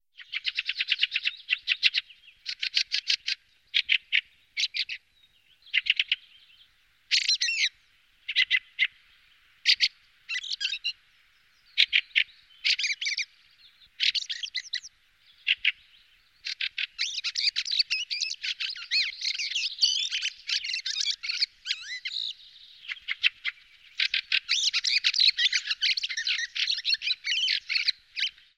thrushes-sound